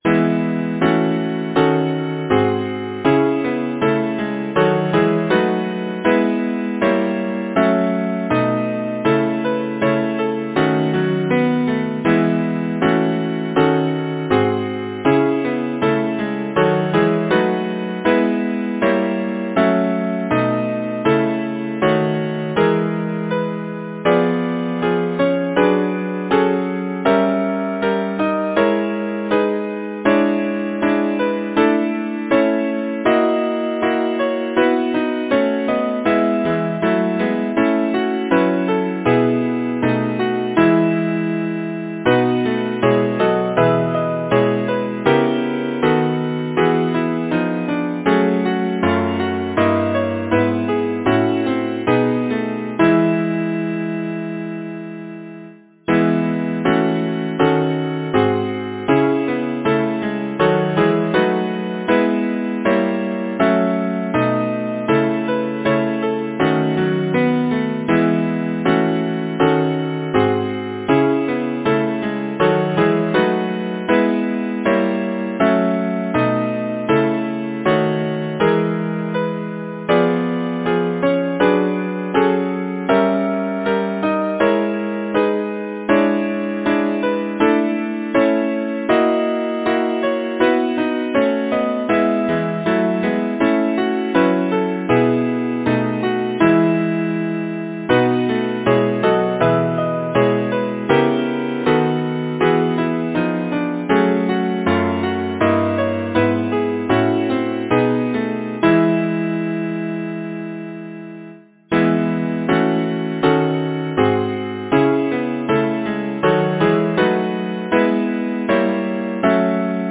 Title: Hymn to Diana Composer: Arnold Duncan Culley Lyricist: Ben Jonson Number of voices: 4vv Voicing: SATB, minor A divisi Genre: Secular, Partsong
Language: English Instruments: A cappella